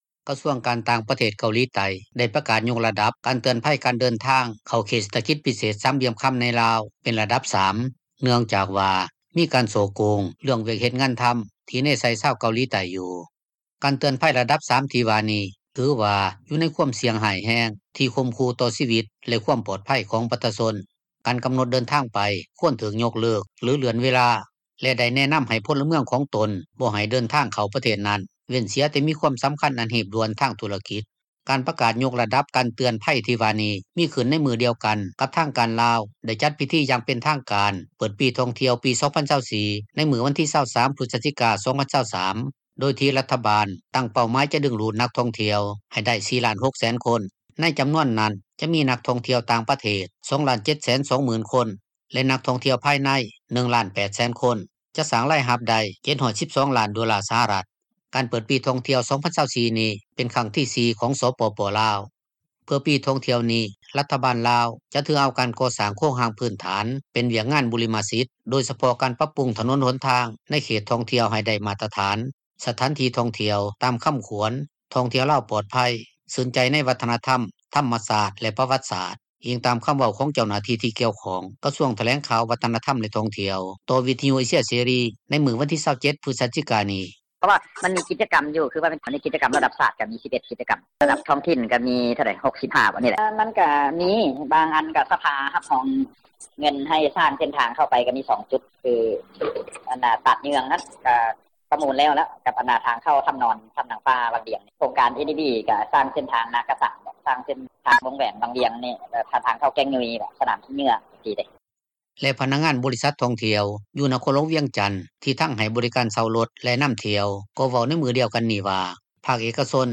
ປະຊາຊົນ ຢູ່ແຂວງວຽງຈັນ ເວົ້າໃນມື້ດຽວກັນນີ້ວ່າ ການເປີດປີທ່ຽວ 2024 ນີ້ ຖືວ່າເປັນໂອກາດດີ ທີ່ຈະເຮັດໃຫ້ປະຊາຊົນຈະມີຢູ່ ມີກິນ, ຈະມີລາຍໄດ້ຈາກການຜລິດກະສິກັມ ແລະຫັດຖະກັມ.
ໃນຂະນະດຽວກັນ ຊາວນະຄອນຫລວງວຽງຈັນ ນາງນຶ່ງເວົ້າວ່າ ມີຄວາມຍິນດີນໍາປີທ່ອງທ່ຽວລາວ 2024 ທີ່ຈະມາເຖິງນີ້, ຈະມີວຽກເຮັດງານທໍາ ໃຫ້ປະຊາຊົນ.